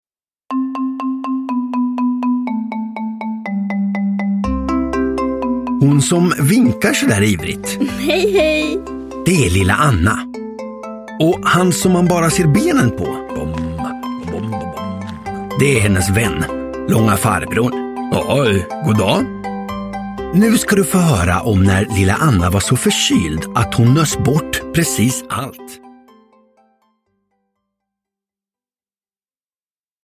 När Lilla Anna var förkyld – Ljudbok – Laddas ner